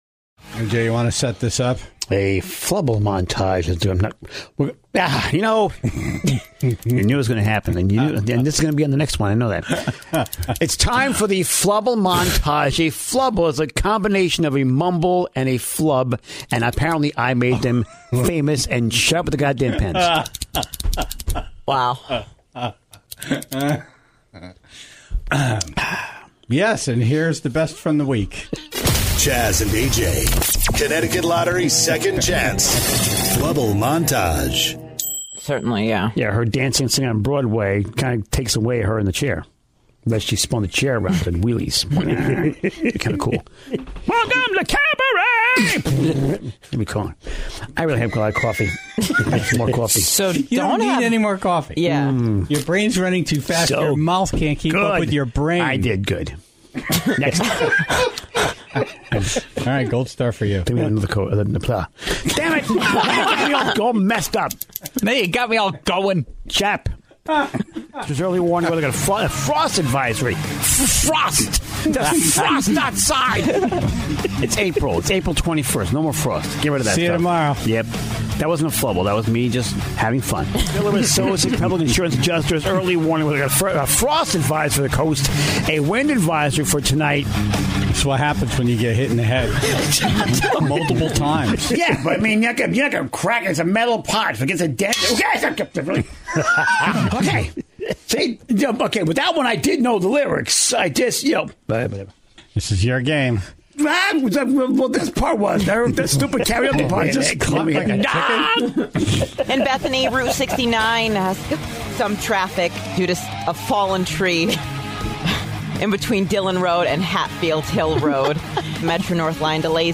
compile every mistake into a montage